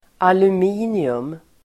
Uttal: [alum'i:nium]